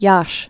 (yäsh, yäshē)